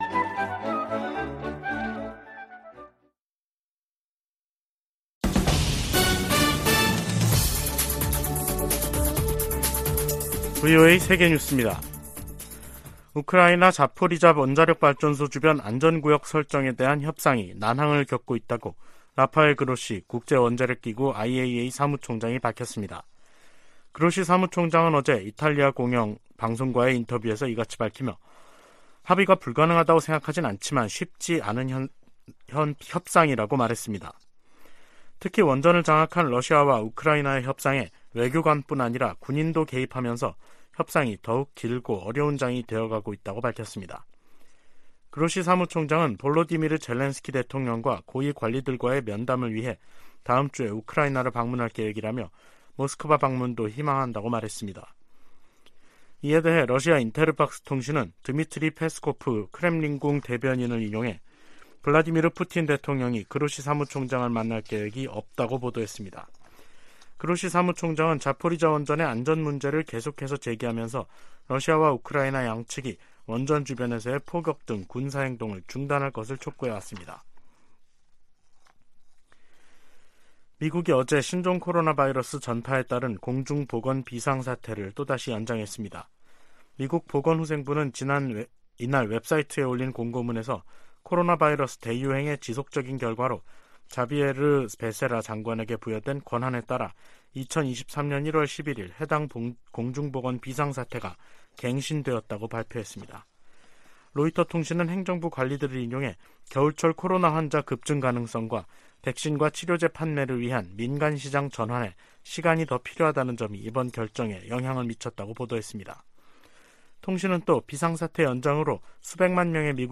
VOA 한국어 간판 뉴스 프로그램 '뉴스 투데이', 2023년 1월 12일 2부 방송입니다. 윤석열 한국 대통령이 핵무기 개발 등 북한의 도발과 안보 위협에 대응한 자체 핵 무장 가능성을 언급했습니다. 미국과 일본의 외교・국방 장관이 워싱턴에서 회담을 갖고 북한의 탄도미사일 도발 등에 대응해 미한일 3자 협력을 강화하기로 거듭 확인했습니다.